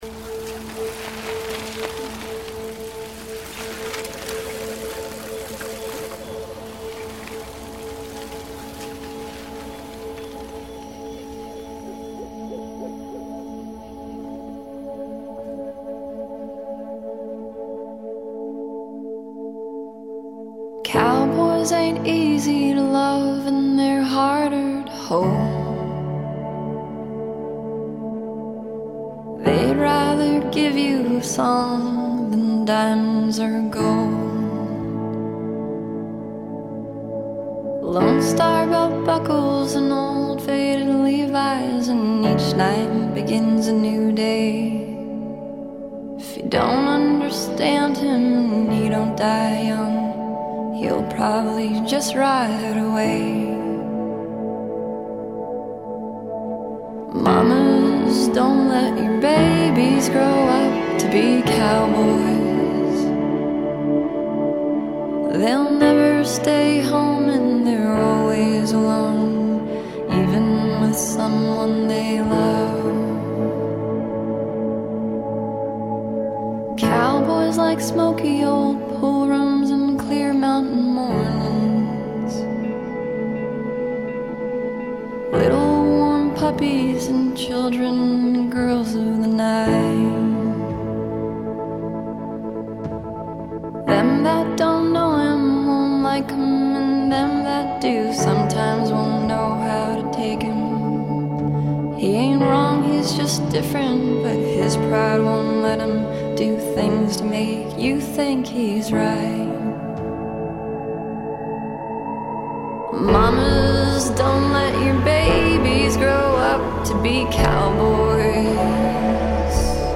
Spooky and sweet…